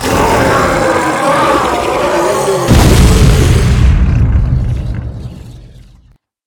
combat / enemy / baurg / die2.ogg
die2.ogg